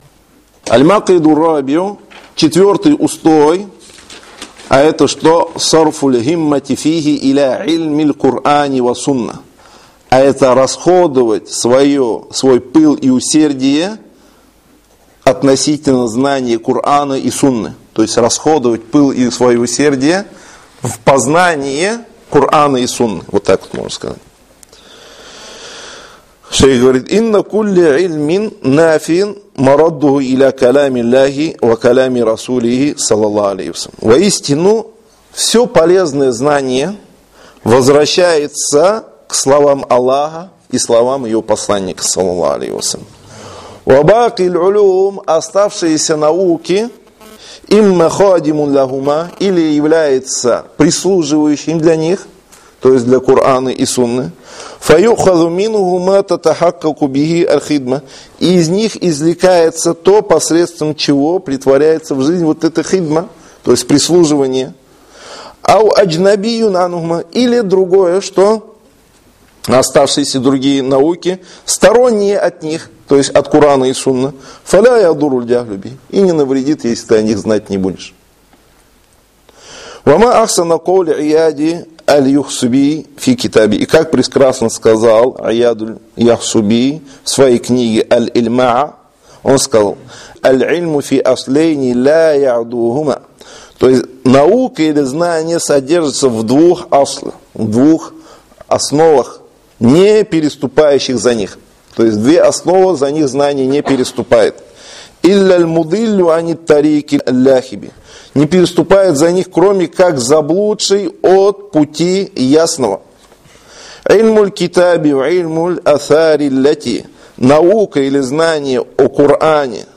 «Возвеличивание знаний» — лекции по сокращенной версии книги «Возвеличивание знаний» шейха Салиха аль-’Усейми, да хранит его Аллах.